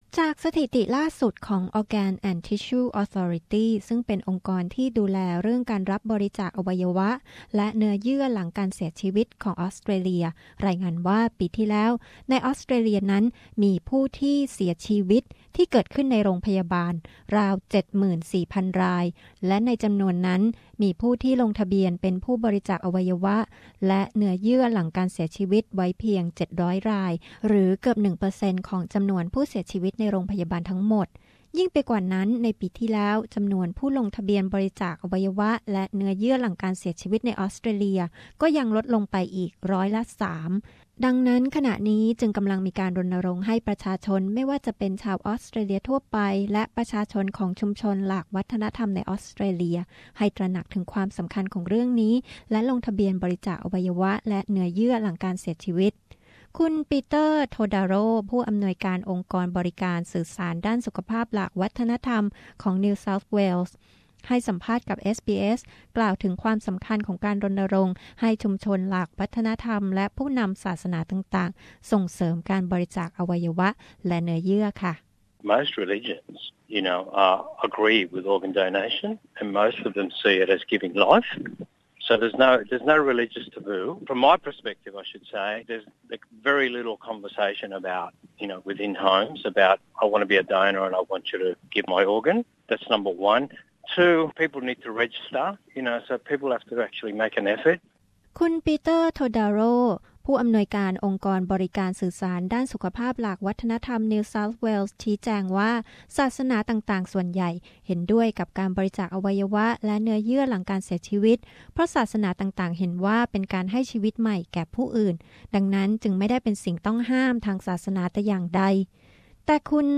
เรายังได้คุยกับคนไทยในออสเตรเลียสองท่าน